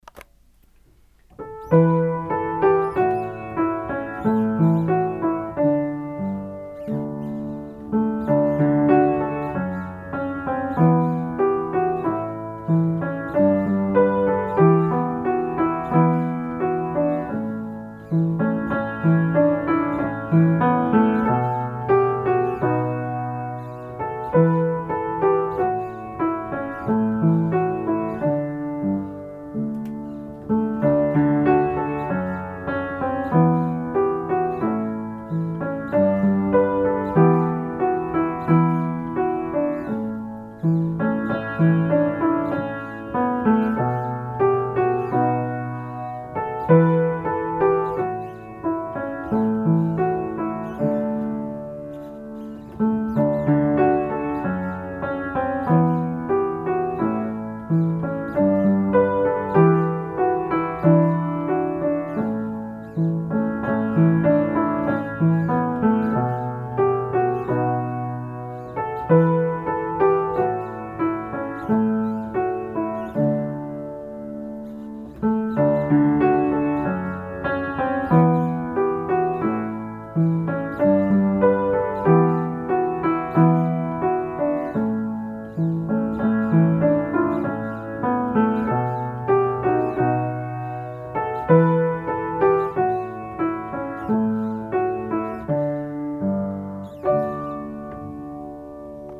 - Klaver indspilning